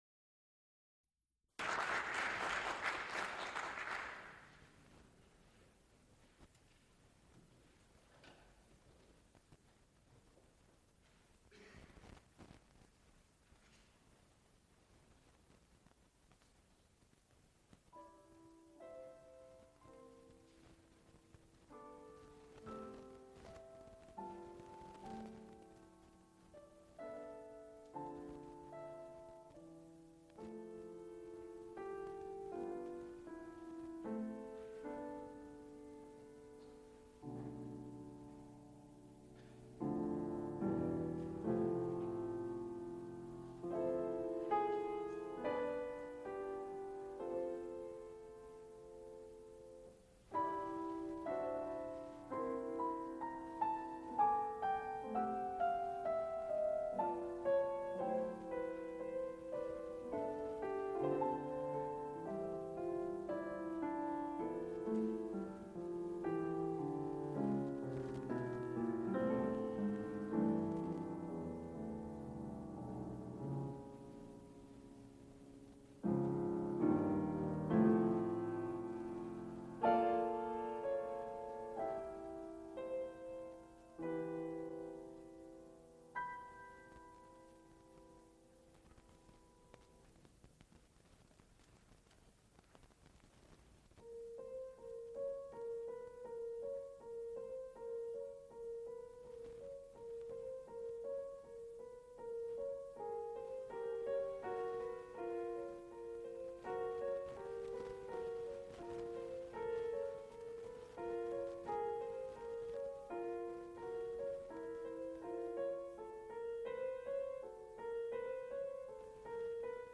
Extent 4 audiotape reels : analog, quarter track, 7 1/2 ips ; 7 in.
musical performances
Piano music
Flute music Songs (High voice) with piano